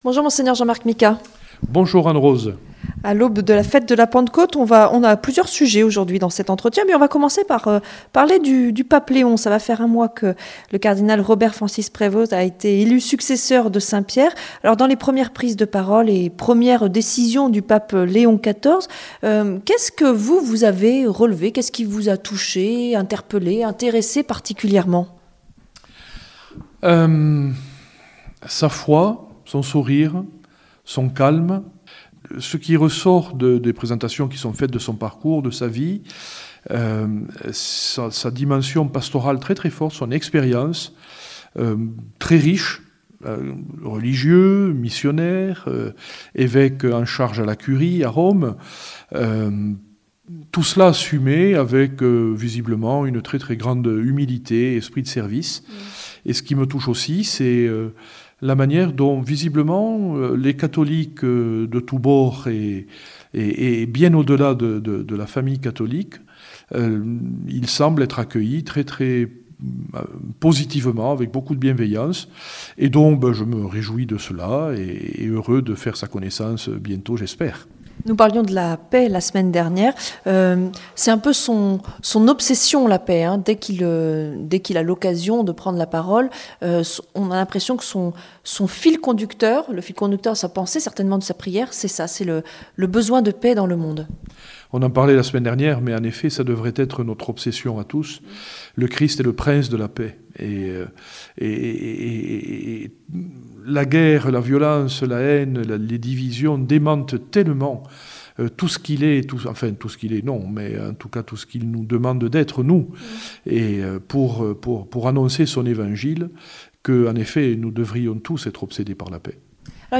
Mgr Jean-Marc Micas 2 mai 2026 0h00 Mgr Jean-Marc Micas Mgr Jean-Marc Micas est évêque de Tarbes et Lourdes, en ce sens qu'il est aussi le "Gardien de la Grotte". Pour cet entretien hebdomadaire, il nous…